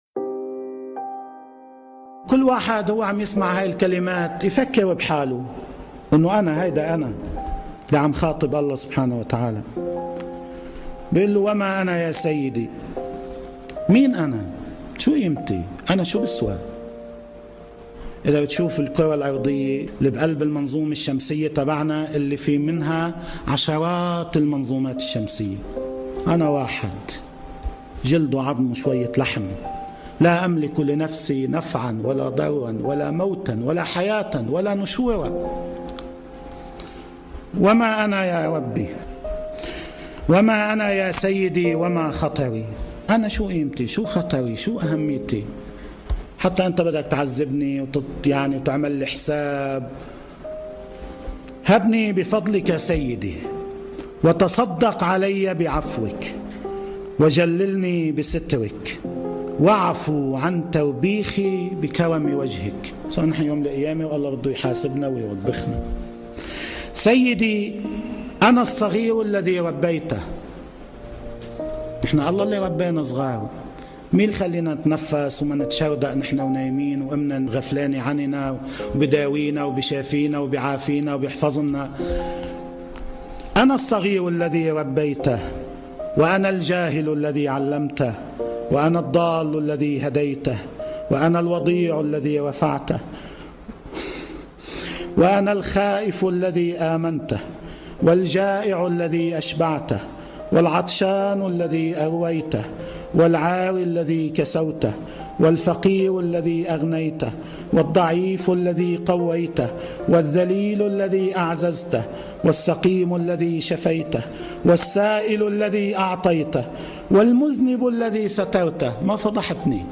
الدعاء الذي يجعلك نكلم الله بروحك بعيدا عن كل الماديان " وما انا يارب وما خطري" مقطع من دعاء ابي حمزة الثمالي بصوت سماحة السيد حسن نصر الله.